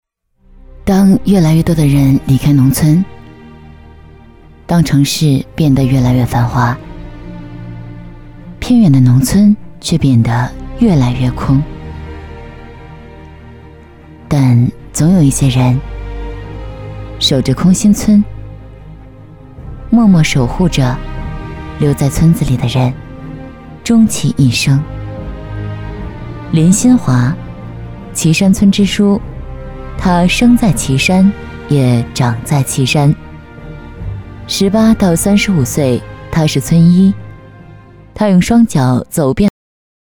配音风格： 讲述 时尚